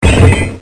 cartoon10.mp3